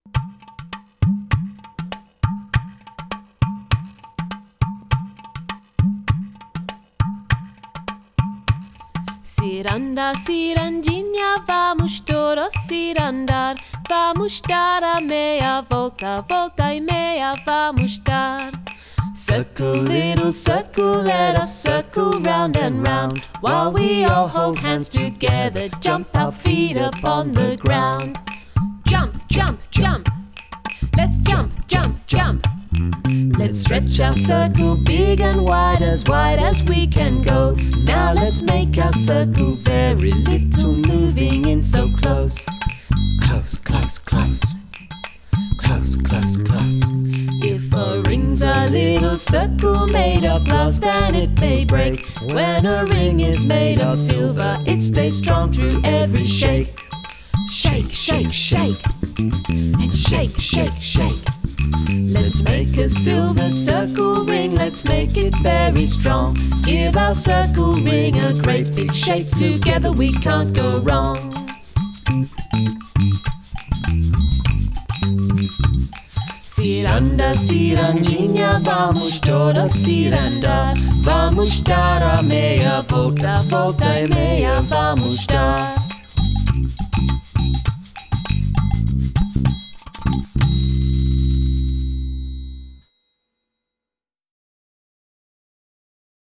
parent friendly children’s album